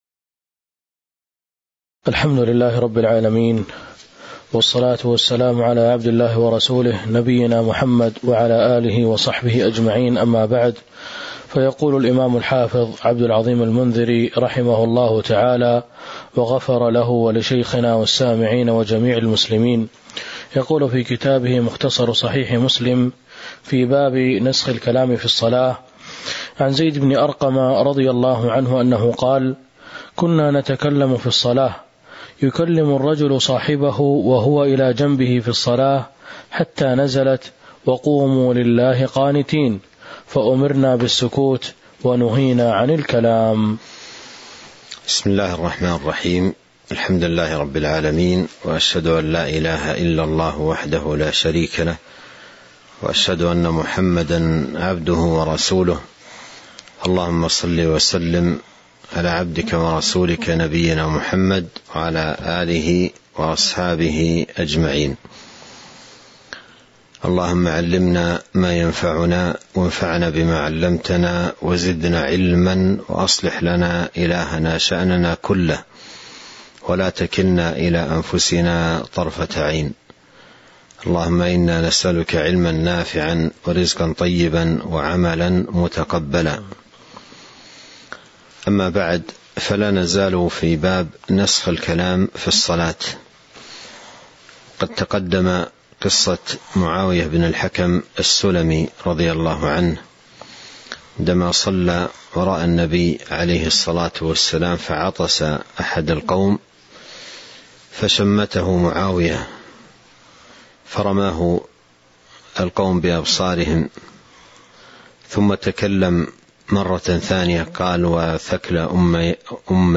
تاريخ النشر ١٥ جمادى الأولى ١٤٤٢ هـ المكان: المسجد النبوي الشيخ